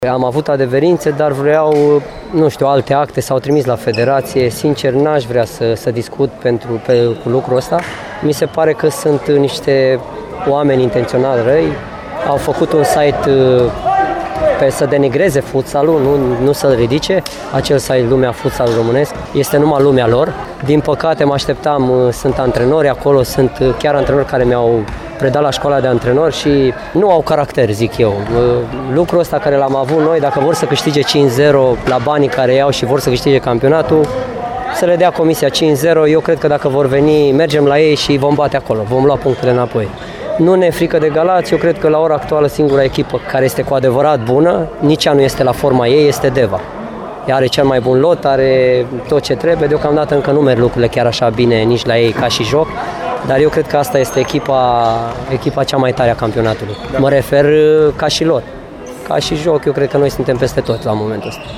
La declarațiile de după partida s-a  vorbit mai mult despre disputele lansate după amânarea partidei dintre Informatica și United Galați, cerute de timișoreni, care au invocat o toxinfecție alimentară.